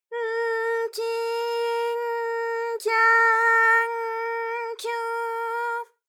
ALYS-DB-001-JPN - First Japanese UTAU vocal library of ALYS.
ky_N_kyi_N_kya_N_kyu.wav